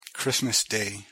pronunciation-en-christmas-day.mp3